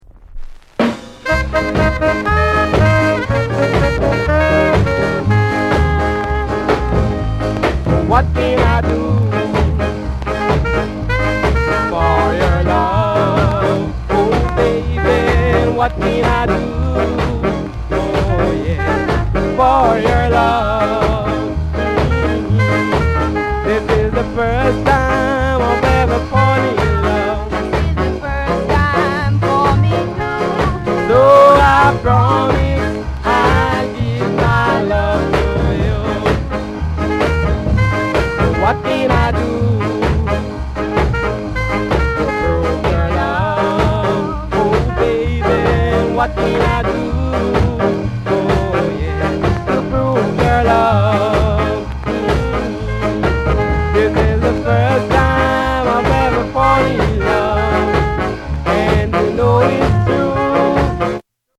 AUTHENTIC SKA INST